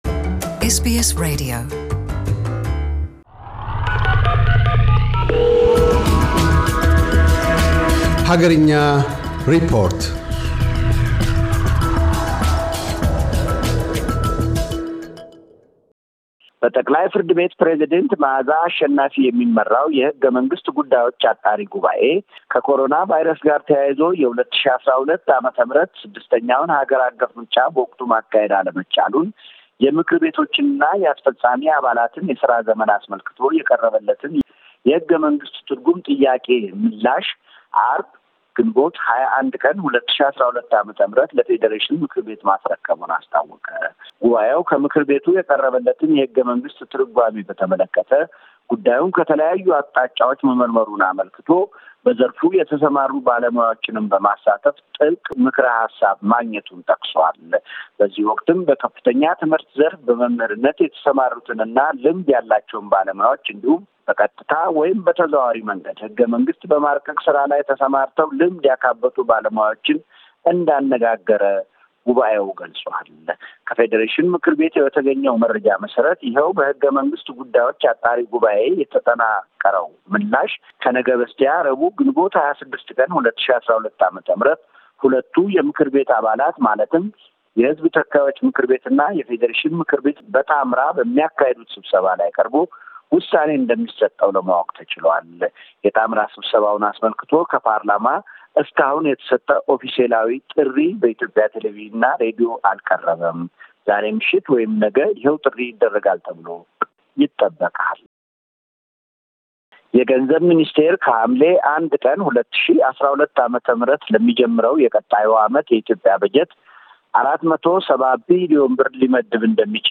አገርኛ ሪፖርት